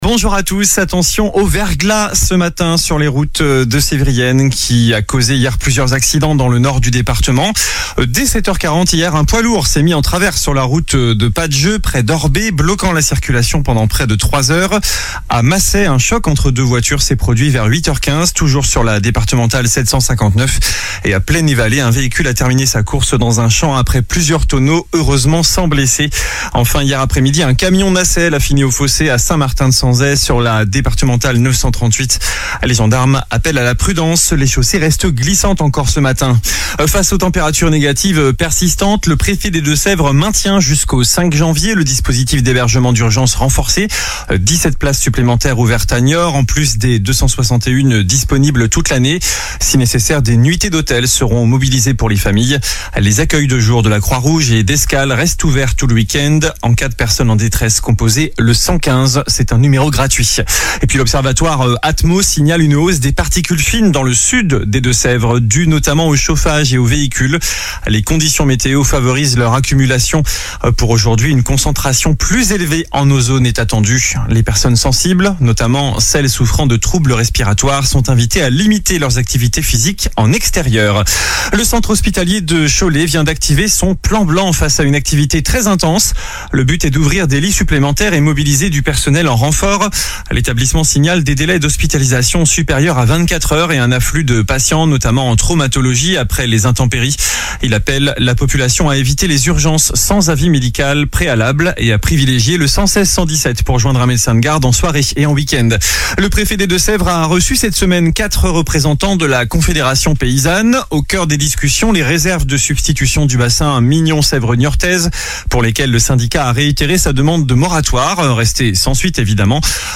infos locales